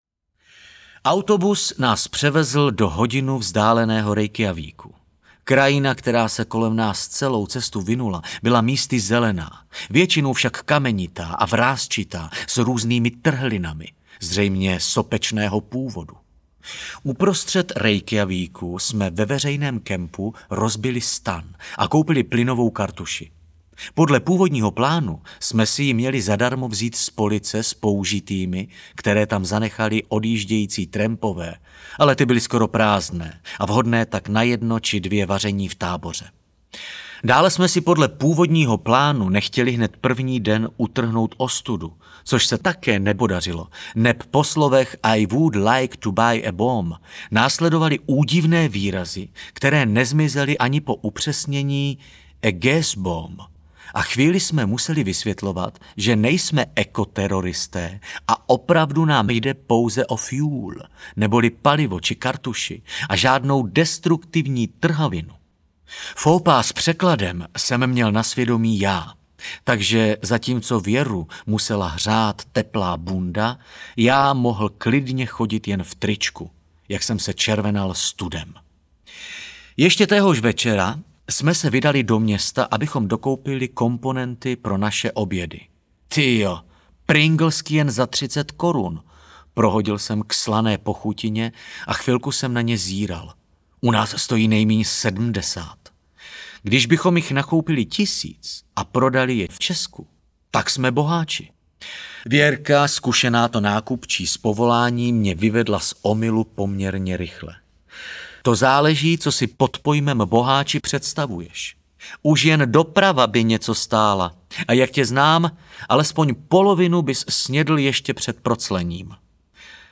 Přežil jsem třiatřicet audiokniha
Ukázka z knihy
prezil-jsem-triatricet-audiokniha